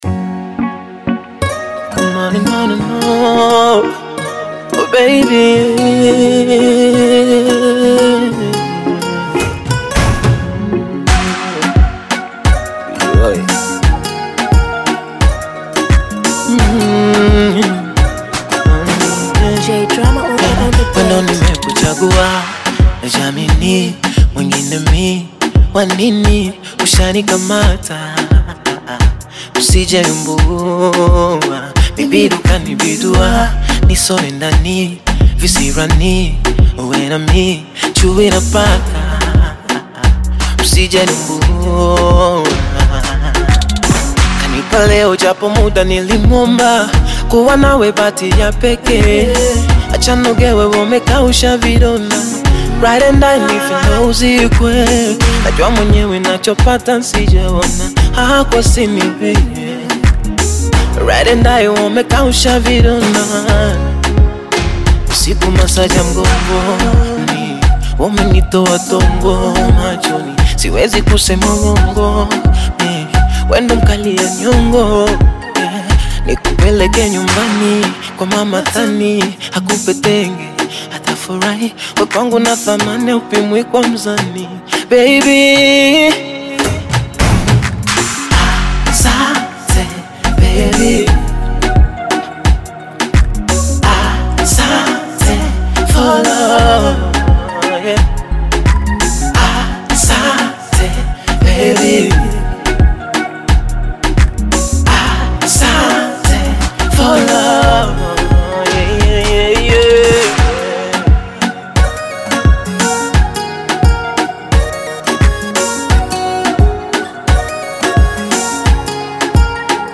Tanzanian Bongo Flava artist, singer and songwriter